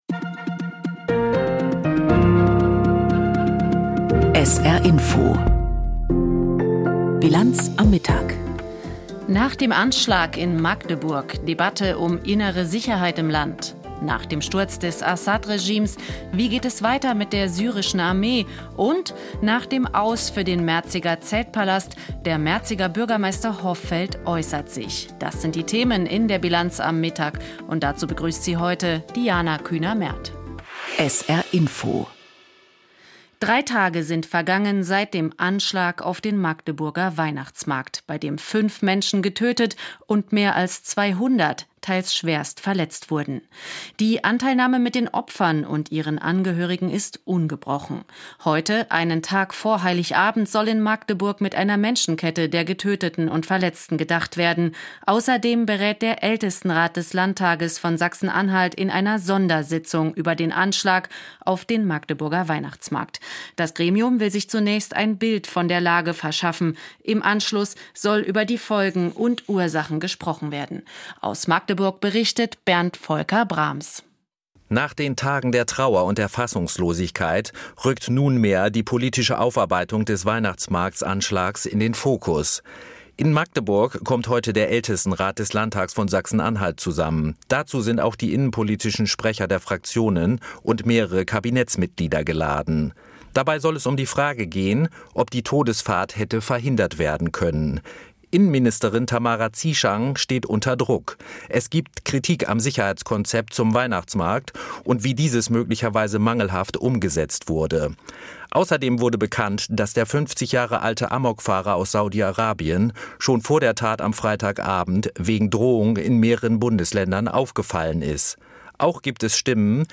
… continue reading 292 episoder # Nachrichten # SR